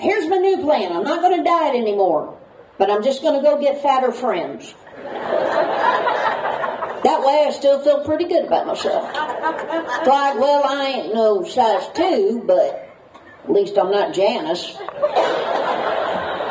Once the sun began to set, activities shifted over the Anderson Building for the annual Beef Fest Awards Ceremony and steak dinner.